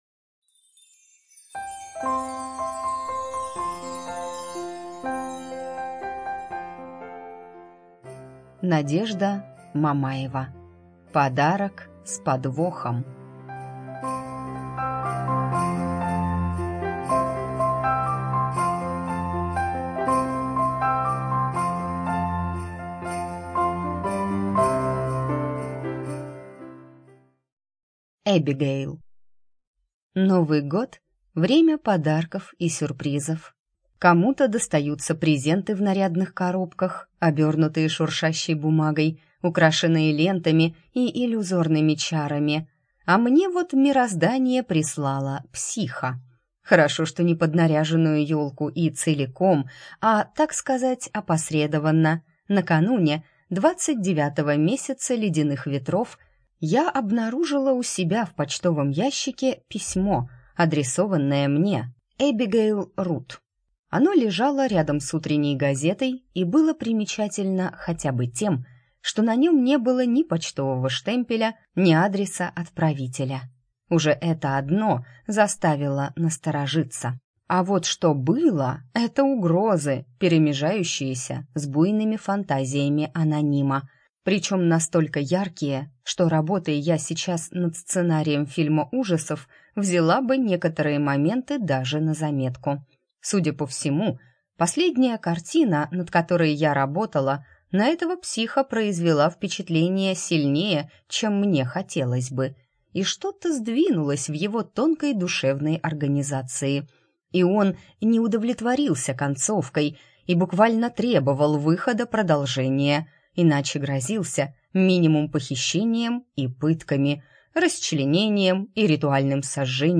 ЖанрЛюбовная проза, Юмор и сатира, Фэнтези